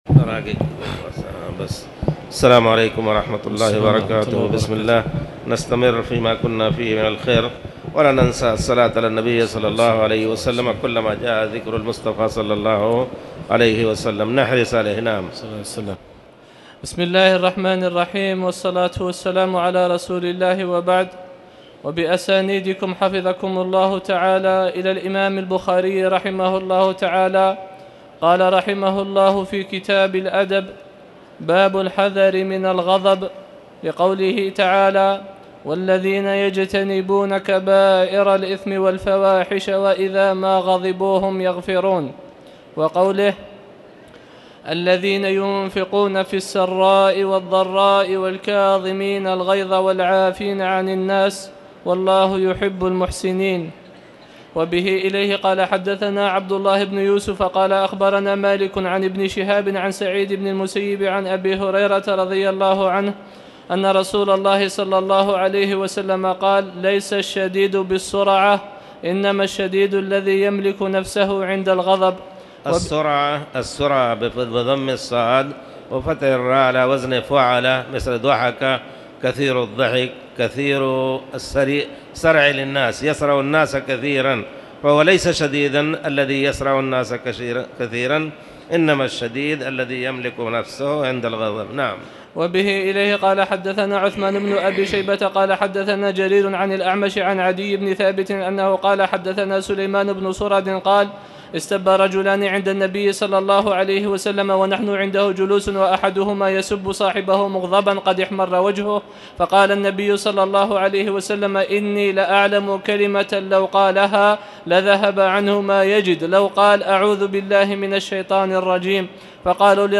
تاريخ النشر ٩ ربيع الثاني ١٤٣٨ هـ المكان: المسجد الحرام الشيخ